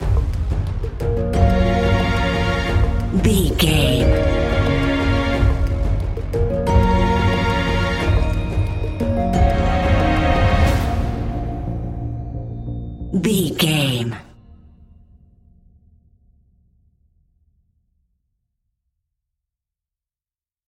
Scary Background Industrial Music 15 Sec.
Aeolian/Minor
A♭
ominous
dark
eerie
synthesizer
strings
instrumentals
horror music